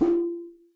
timpsnare_pp.wav